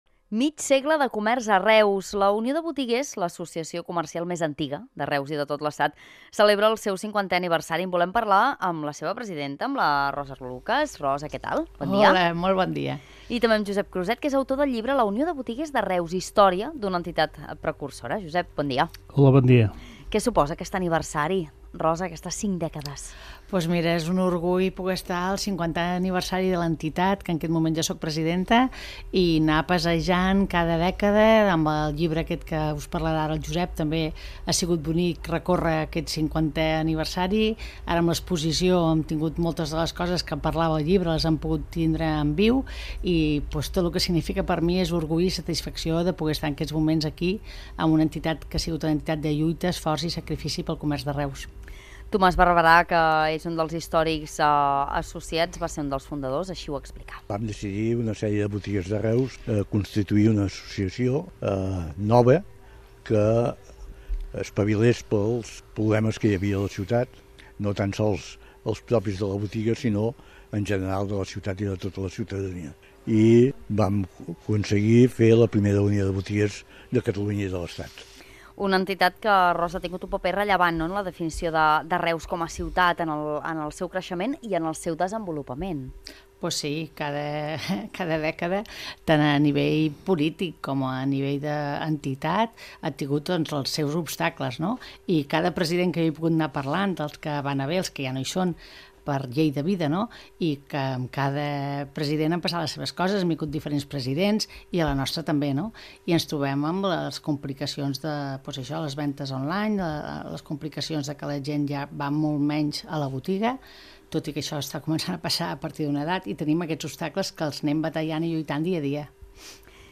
Entrevista a la Unió de Botiguers de Reus